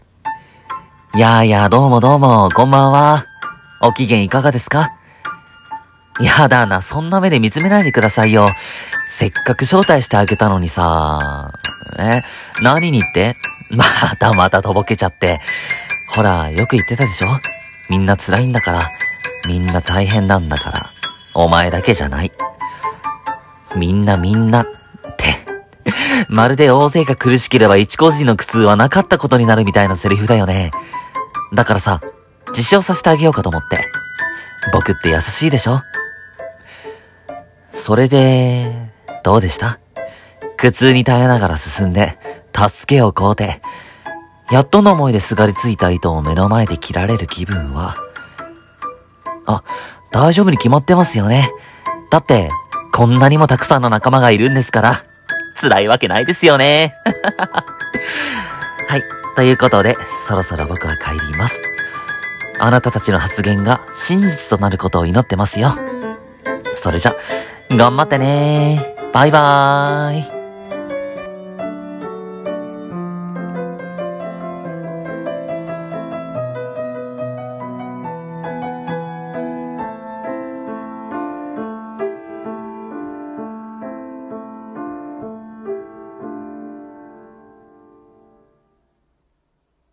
一人声劇:『言霊』